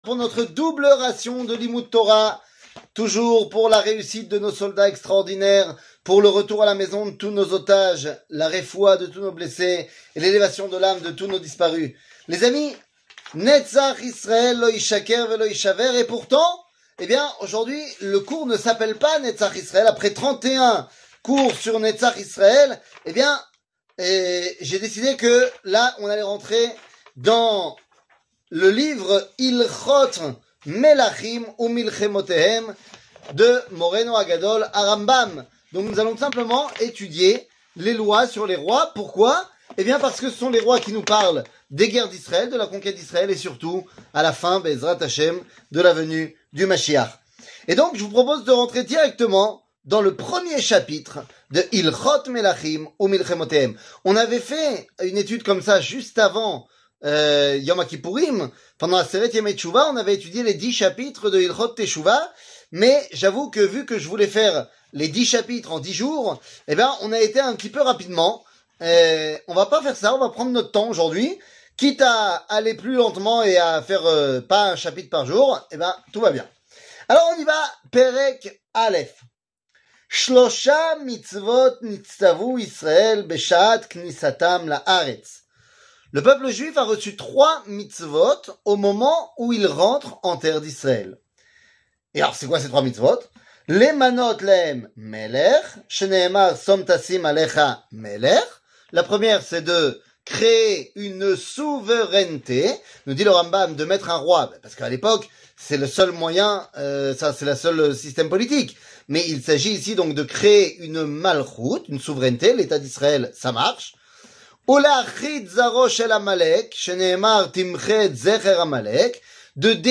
שיעור מ 20 נובמבר 2023